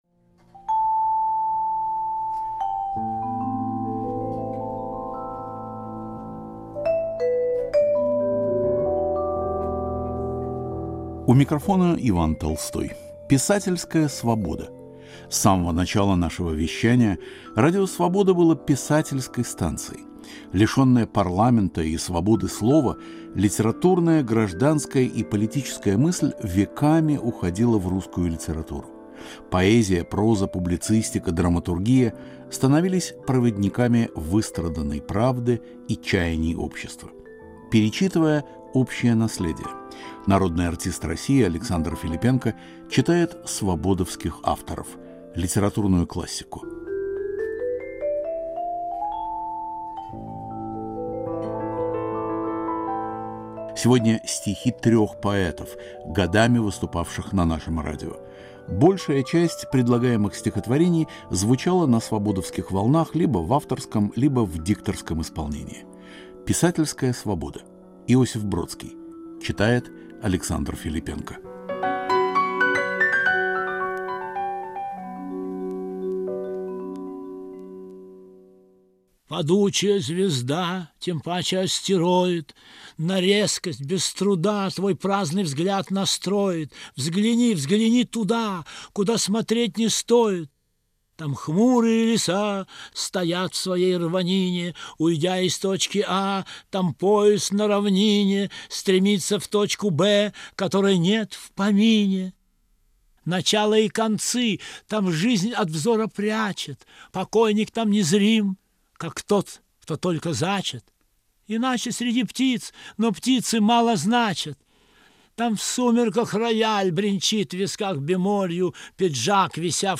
Александр Филиппенко читает стихи Иосифа Бродского, Алексея Цветкова и Льва Лосева
Народный артист России Александр Филиппенко читает свободовских авторов - литературную классику. Сегодня стихи трех поэтов, годами выступавших на нашем радио – Иосифа Бродского, Алексея Цветкова и Льва Лосева.